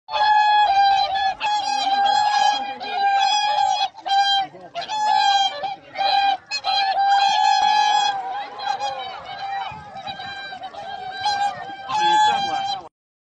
大天鹅鸣叫声